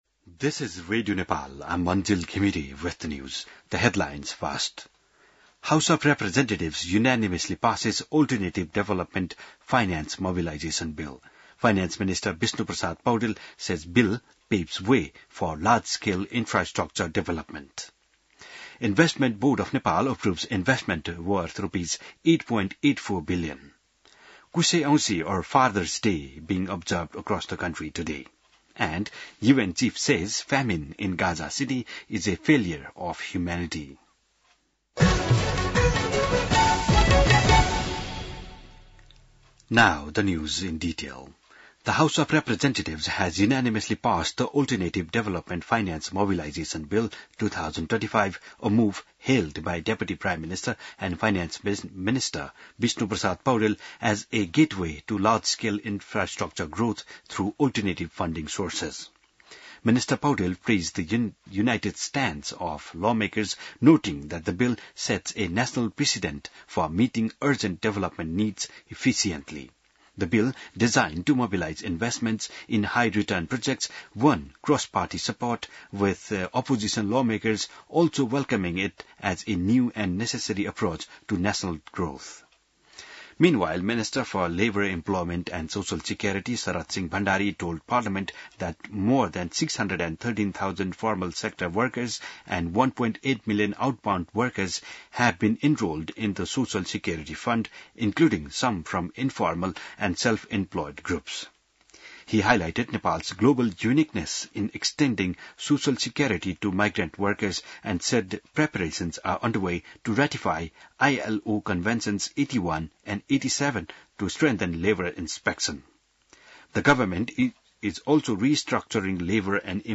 बिहान ८ बजेको अङ्ग्रेजी समाचार : ७ भदौ , २०८२